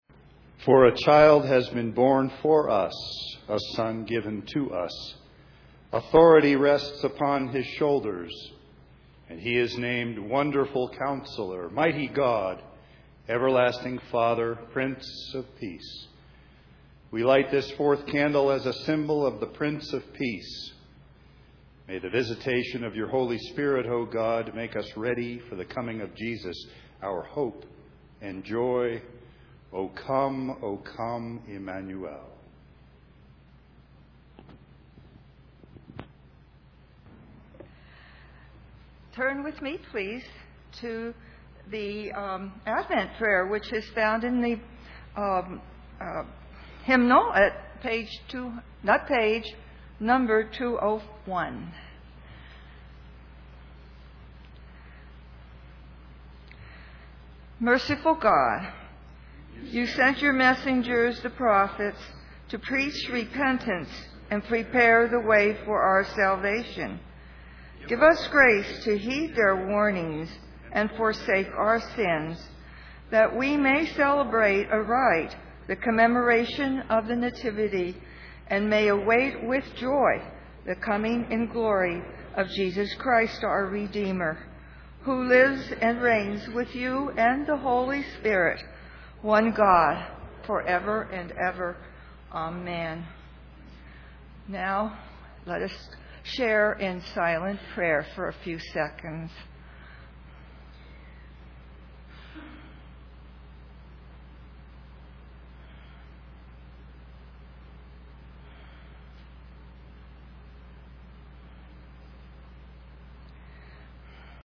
Sunday Service
04LightingoftheAdventCandle.mp3